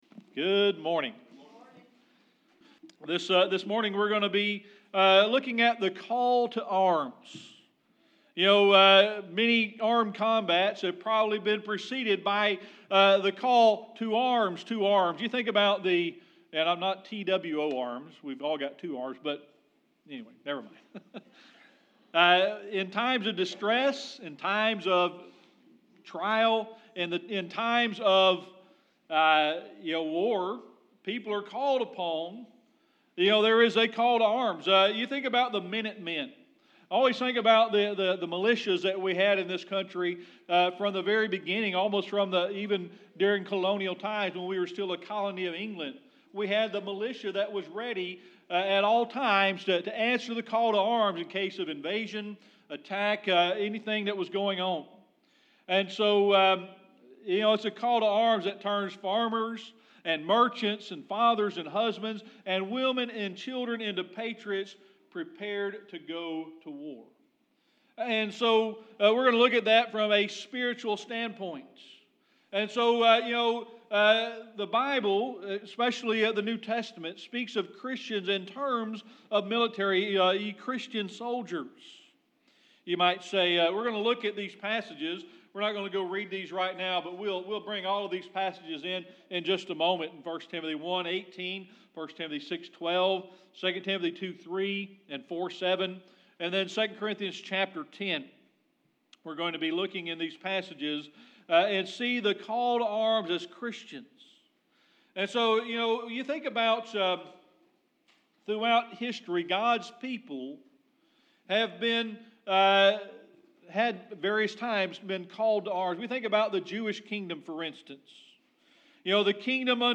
1 Timothy 1:18 Service Type: Sunday Morning Worship This morning we're going to be looking at the call to arms.